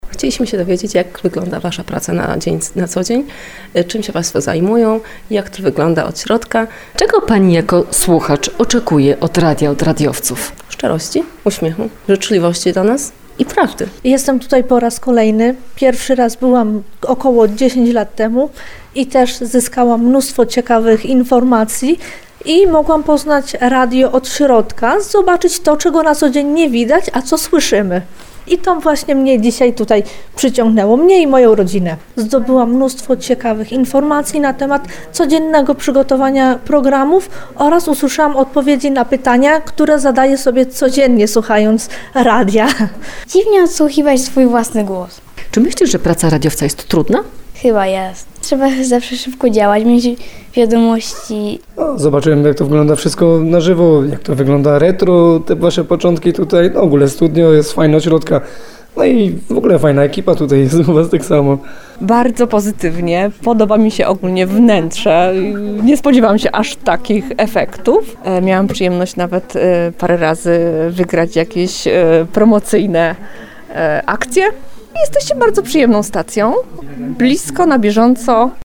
Do tarnowskiej siedziby Radia RDN Małopolska przyszli prawdziwi miłośnicy radia. W ramach Małopolskiej Nocy Naukowców drzwi naszej rozgłośni były otwarte dla wszystkich, których ciekawiło, jak wygląda nasza praca od kuchni.